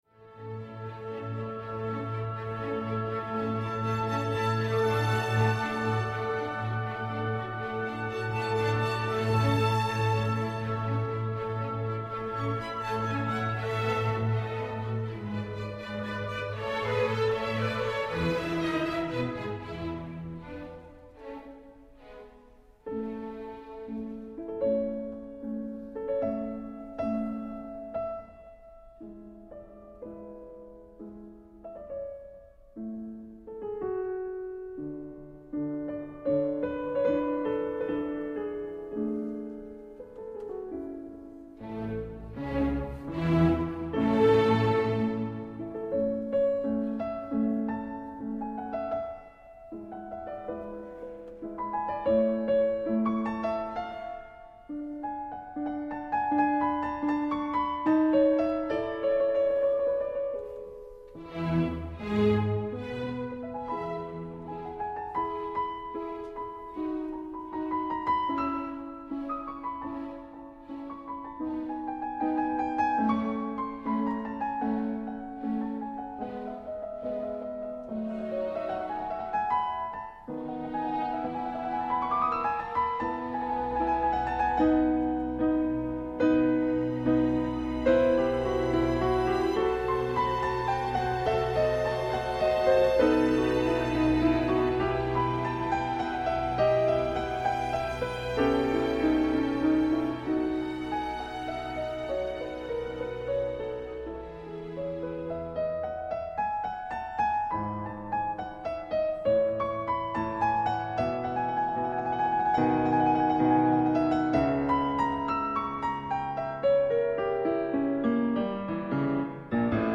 Joseph Haydn – Klavierkonzert D-Dur – 2.Satz Ausschnitt